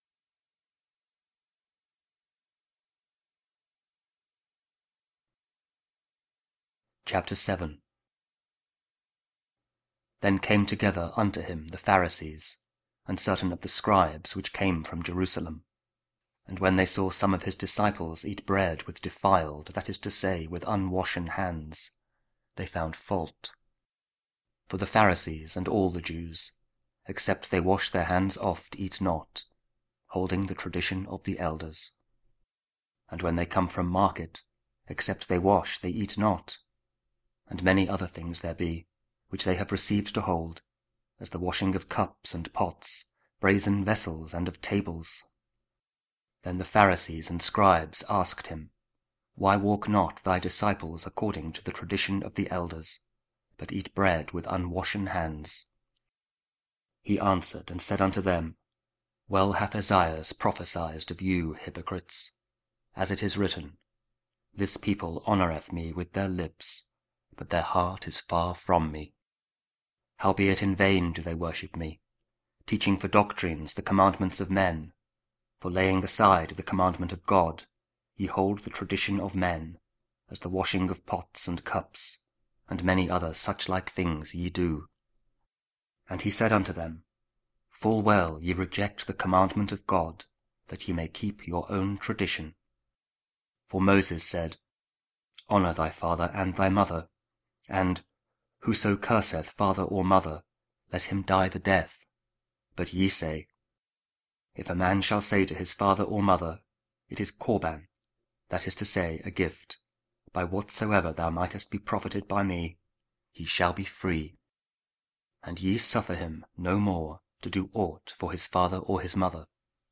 Mark 7: 1-8, 14-15, 21-23 – 22nd Sunday Year B (Audio Bible KJV, Spoken Word)